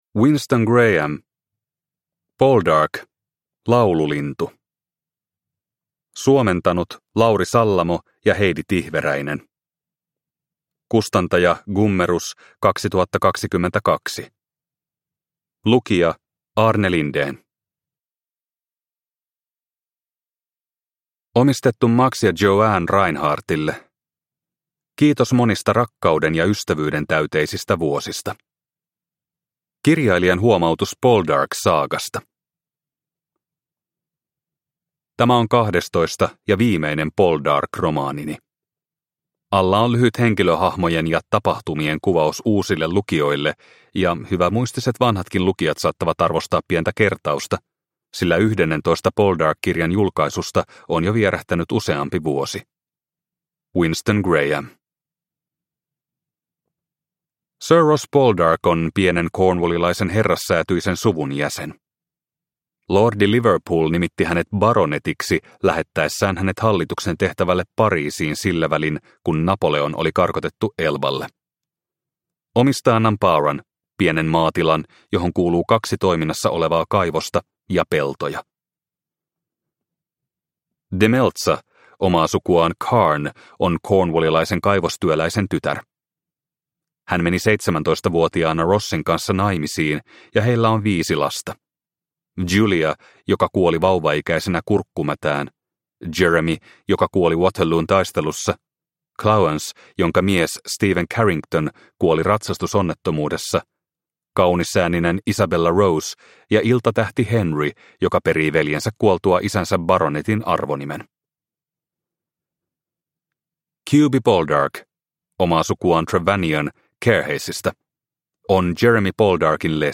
Poldark - Laululintu – Ljudbok – Laddas ner